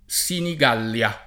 Senig#l’l’a] o Sinigallia [